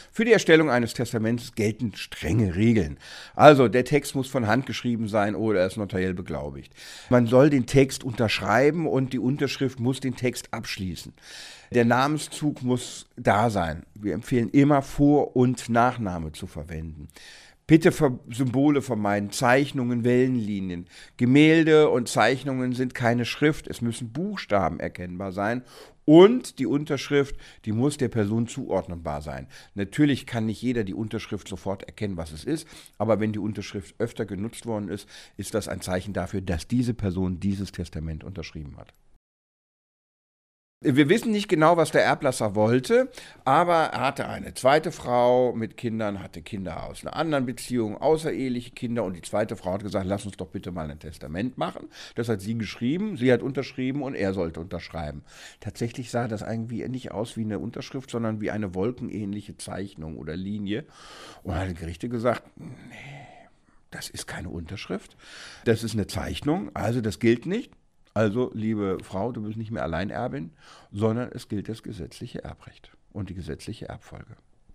Kollegengespräch: Wie vererbt man richtig?